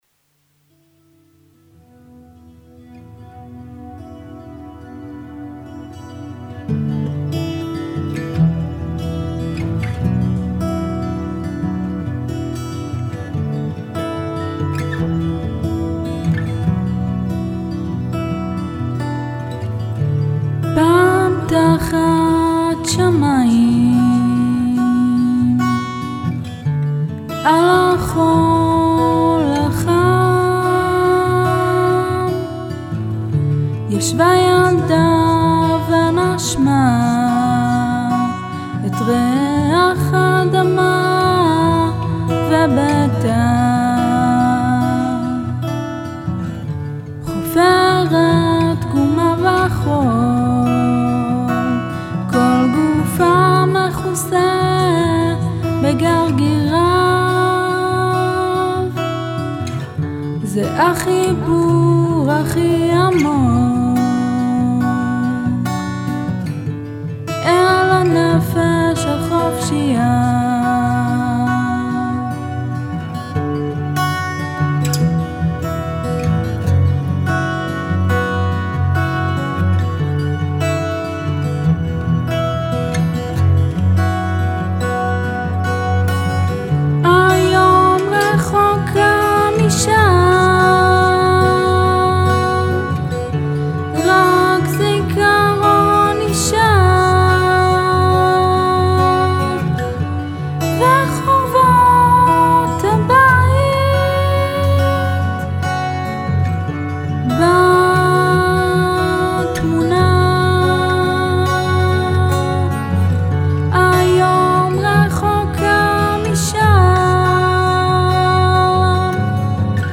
מילים ושירה: אני